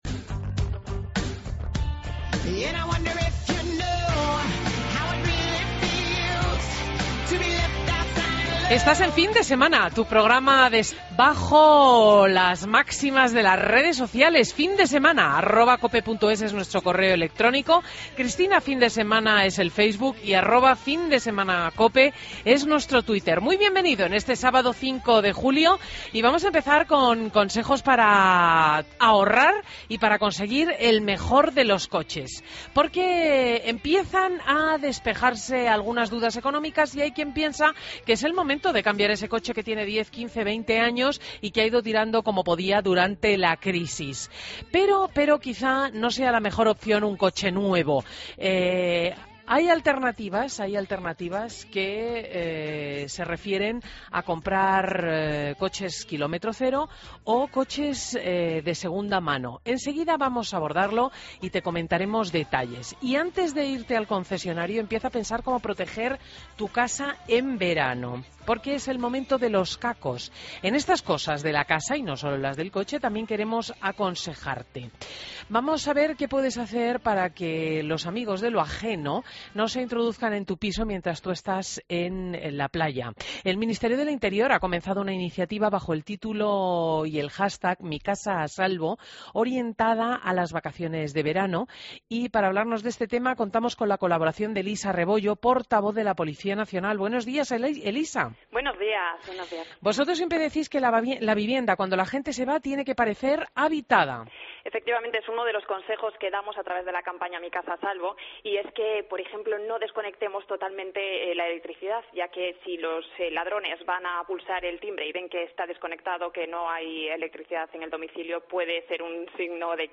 Entrevistas en Fin de Semana Entrevista